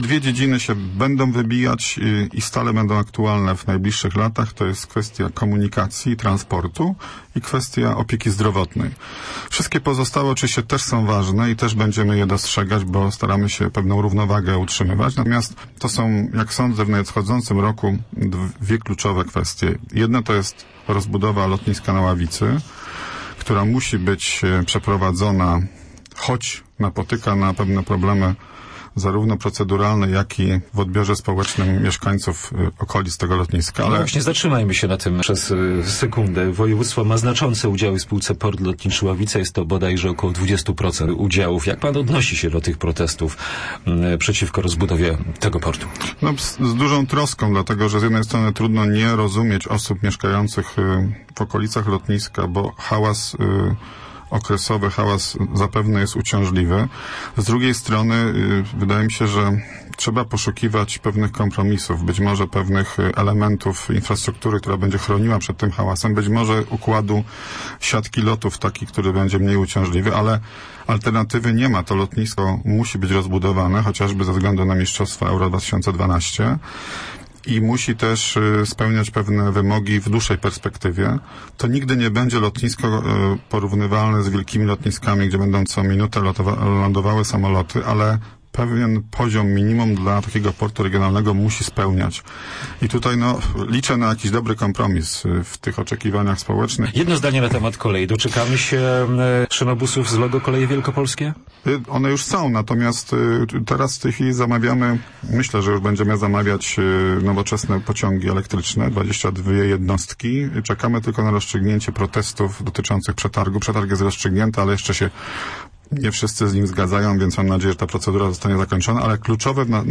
O priorytetach w projektowanym budżecie Wielkopolski na rok 2011 mówił gość poniedziałkowego programu "Z jakiej racji" Marek Woźniak, Marszałek Województwa Wielkopolskiego. Zapowiedział, że województwo najwięcej środków przeznaczy na zdrowie i transport publiczny.